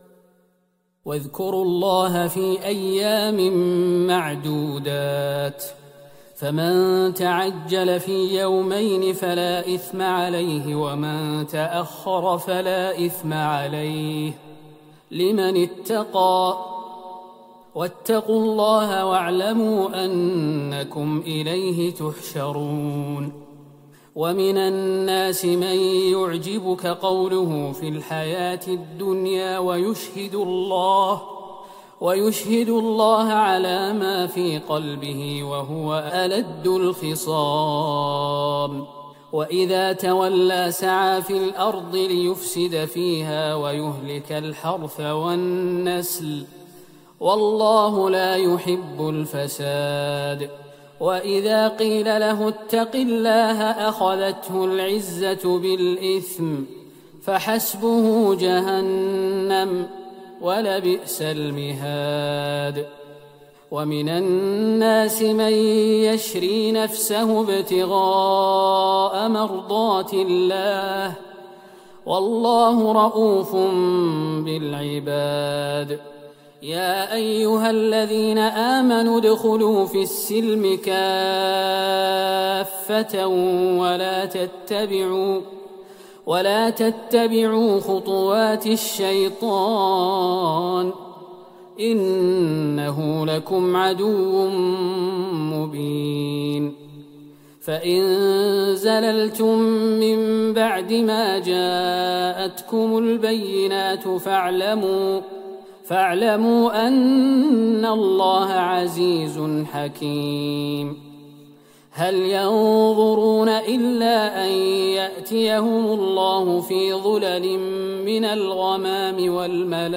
ليلة ٣ رمضان ١٤٤١هـ من سورة البقرة { ٢٠٣- ٢٤٨} > تراويح الحرم النبوي عام 1441 🕌 > التراويح - تلاوات الحرمين